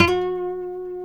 G 4 HAMRNYL.wav